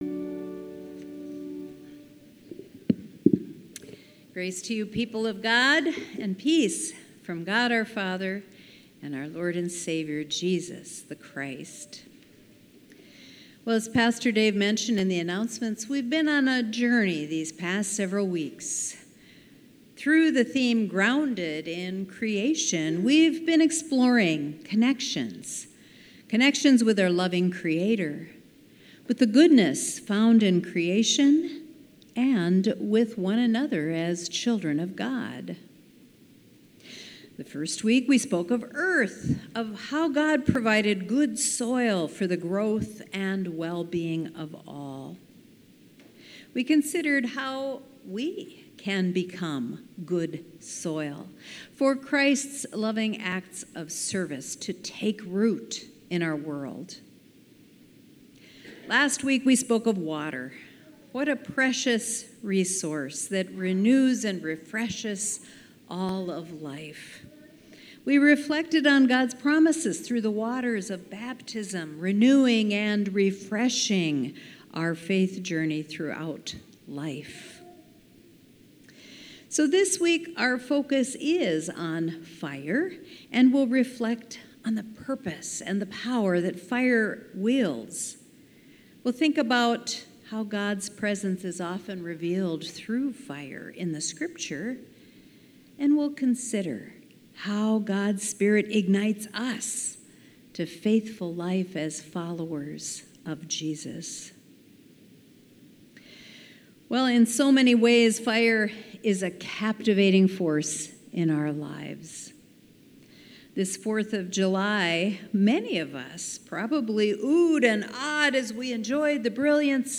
Sermons | Moe Lutheran Church